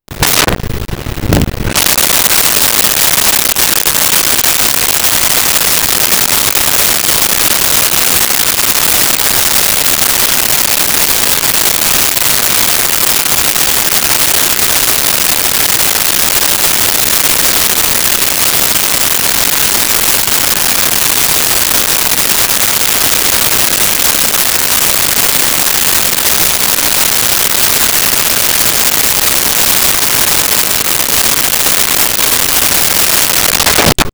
Shower On And Run
Shower On And Run.wav